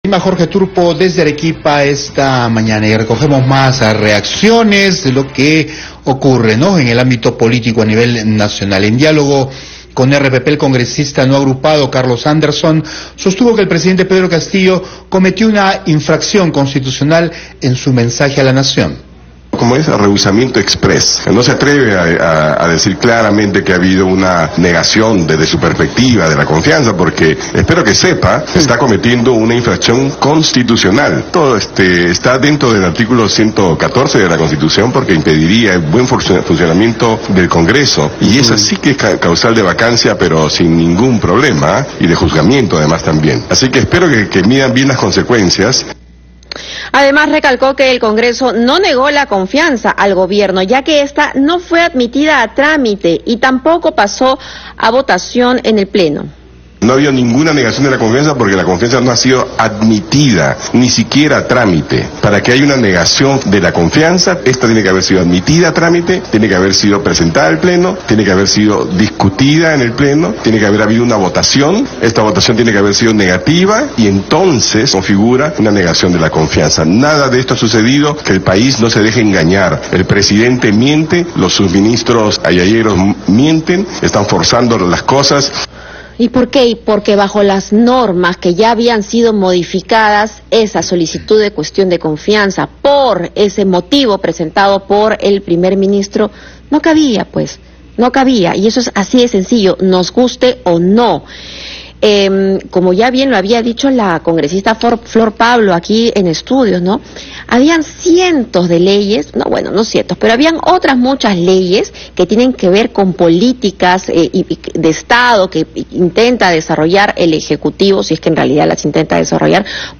El congresista Carlos Anderson, sostuvo que el presidente Pedro Castillo, cometió una infracción constitucional, en su mensaje a la Nación.